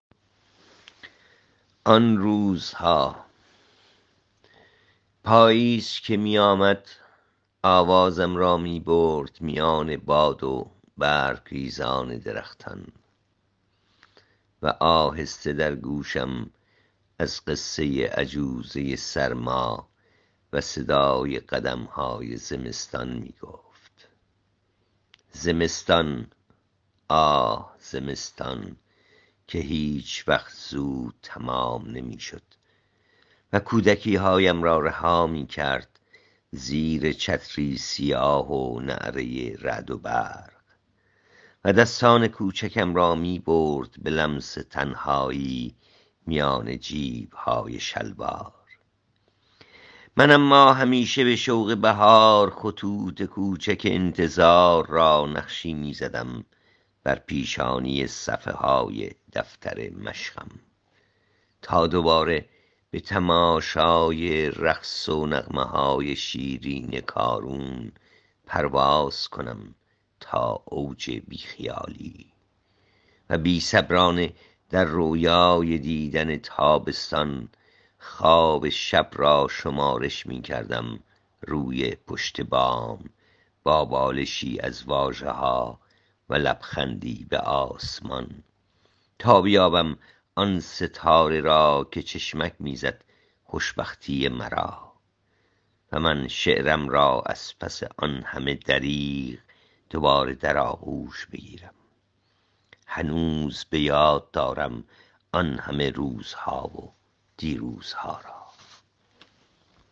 این شعر را می توانید با صدای شاعر بشنوید